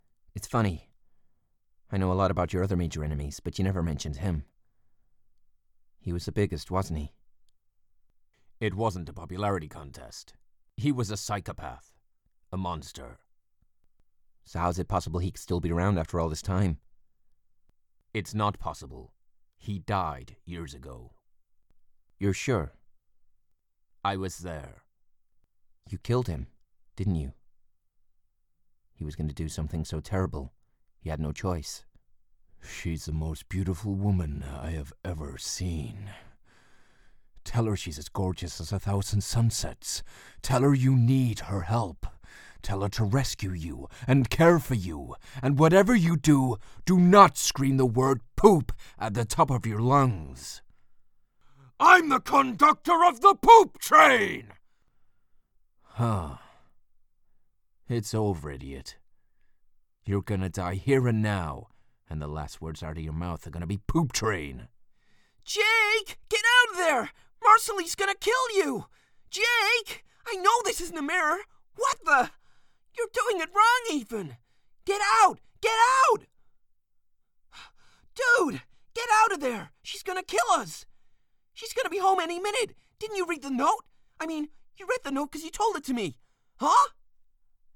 US Reel
• Home Studio
His native north-east is light and amicable, but he can plunge those vocal depths for villainy at a finger click.